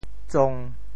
枞（樅） 部首拼音 部首 木 总笔划 8 部外笔划 4 普通话 cōng zōng 潮州发音 潮州 zong1 文 cong1 文 中文解释 枞 <名> cong 枞树。
tsong1.mp3